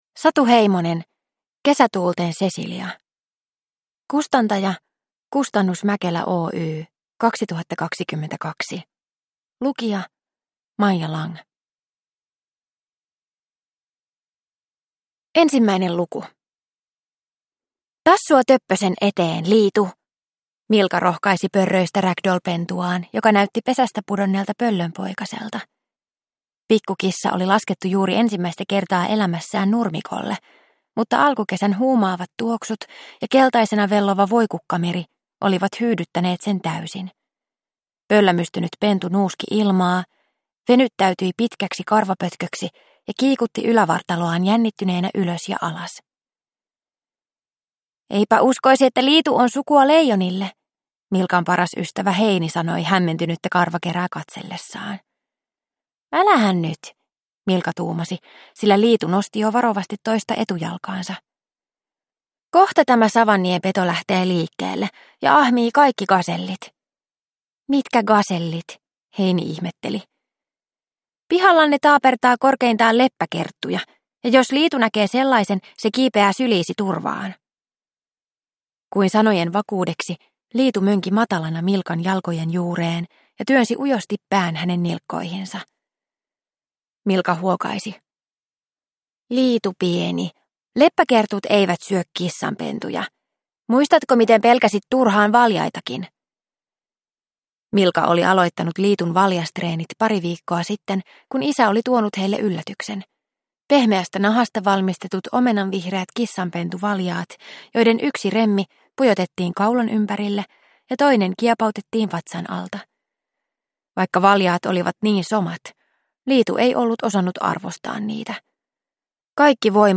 Kesätuulten Cecilia – Ljudbok – Laddas ner